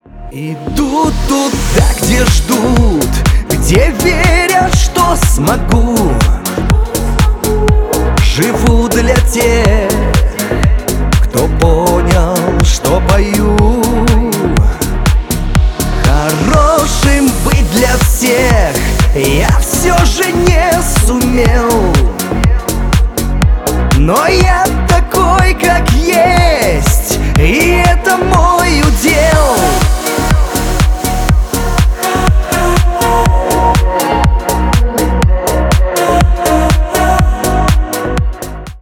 Шансон
грустные